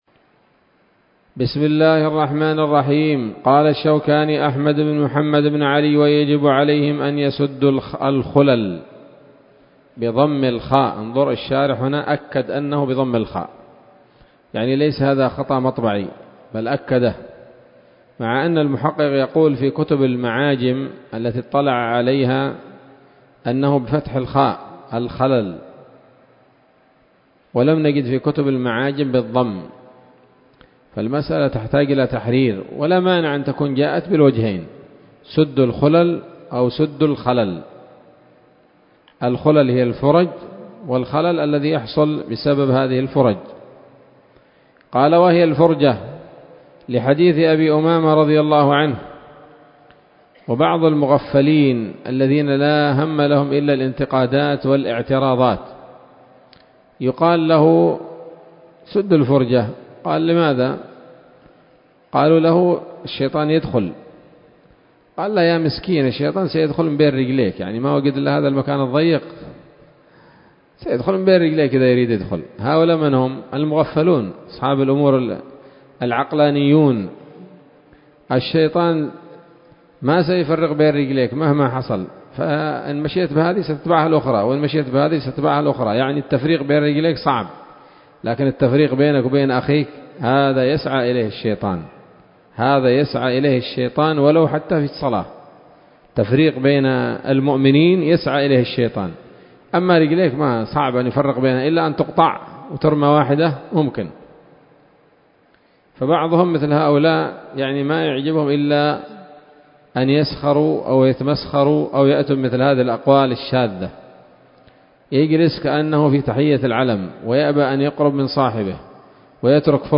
الدرس الحادي والثلاثون من كتاب الصلاة من السموط الذهبية الحاوية للدرر البهية